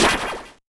ballStart.ogg